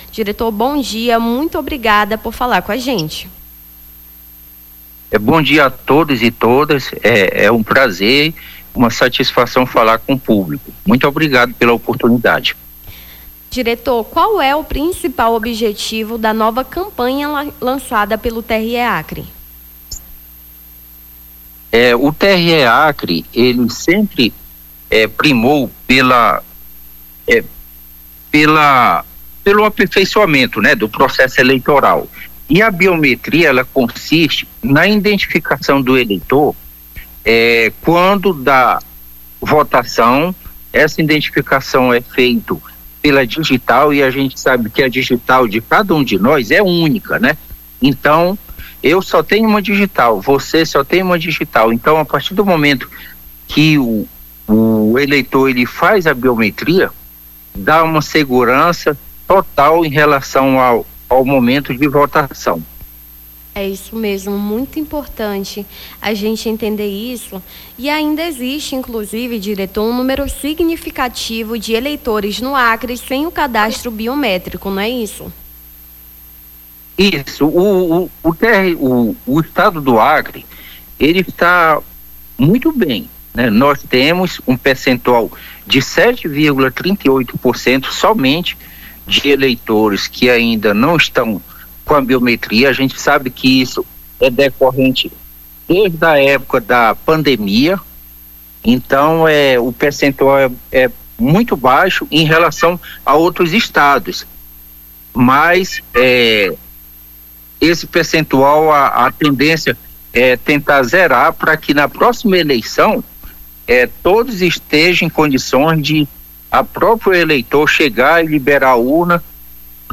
Nome do Artista - CENSURA - ENTREVISTA (BIOMETRIA TRE) 29-04-25.mp3